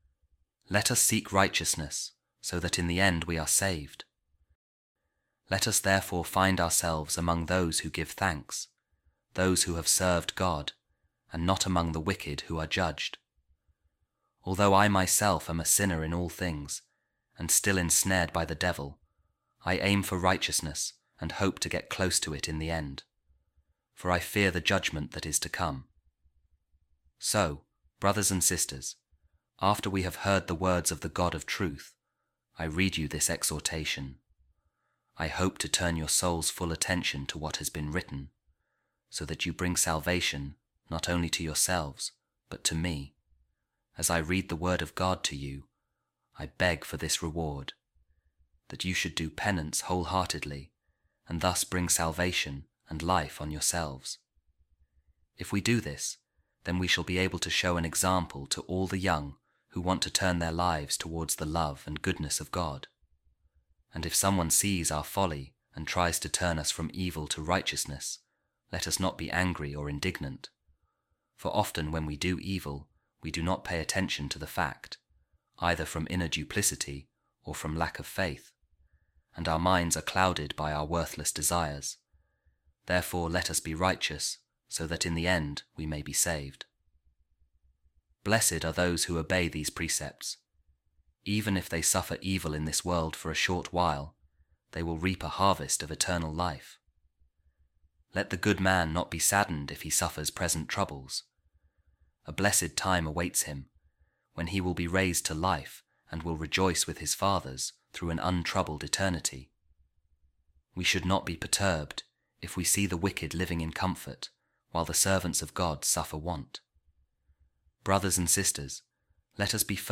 Office Of Readings | Week 32, Saturday, Ordinary Time | A Reading From A Homily Of A Second-Century Author | Let Us Seek Righteousness So That In The End We Are Saved